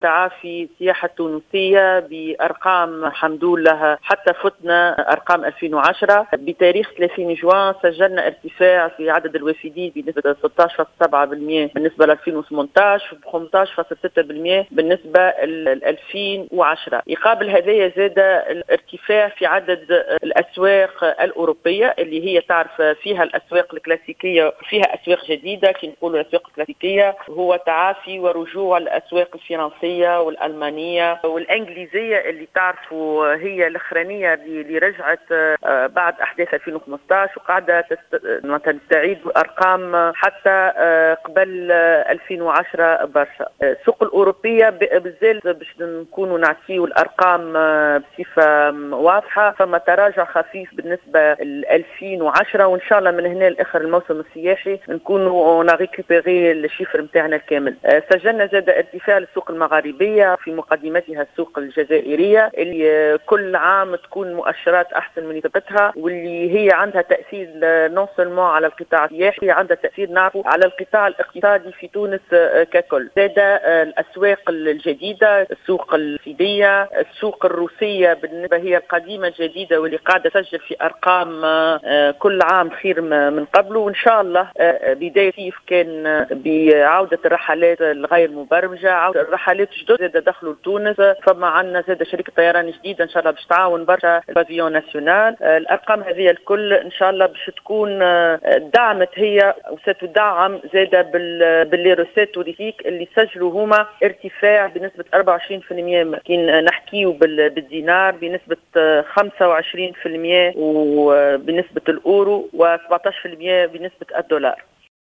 وأضافت الحشاني، في اتصال هاتفي للجوهرة أف-أم أن السياحة التونسية تعافت وأن الأرقام المُسجلة فاقت أرقام سنة 2010 (السنة المرجع).